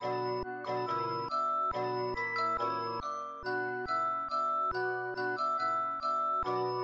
描述：吃一个混合的泡菜/玉米粉，以获得这种特殊的混合湿，干和酸。
Tag: 味酸 压扁 蔬菜 湿 Cocumber 松脆 紧缩